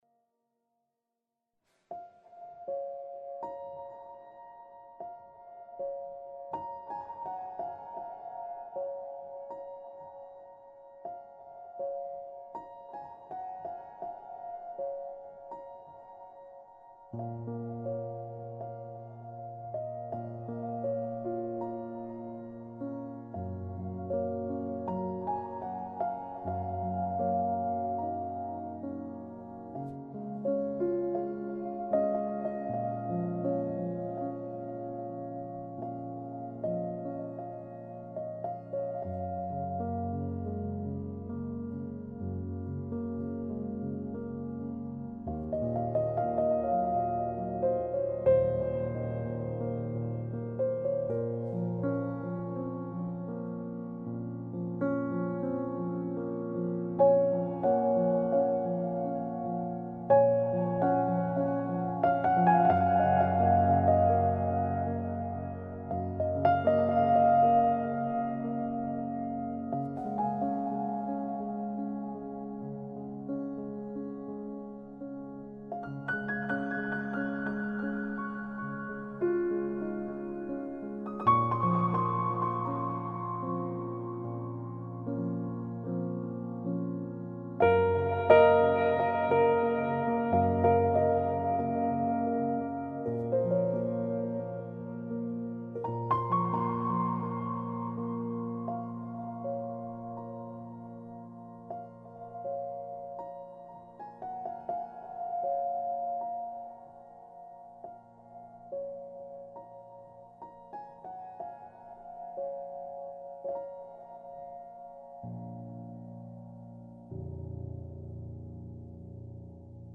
سبک پیانو , موسیقی بی کلام